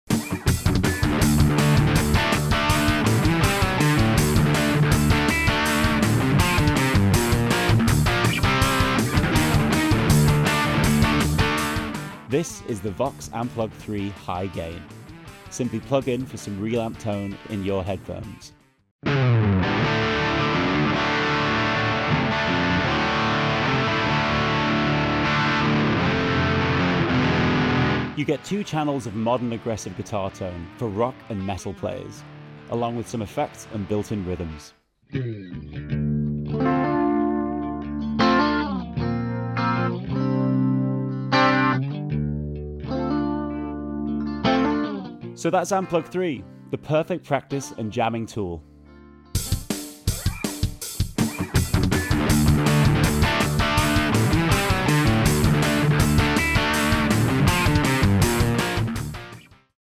The High Gain model offers modern rock and metal tones with a full clean channel and sharp distortion channel, plus the stereo Chorus, Delay, and Reverb, effects with adjustable parameters. Whether you're warming up backstage or practicing at home, this compact headphone amplifier delivers massive tone right in your pocket.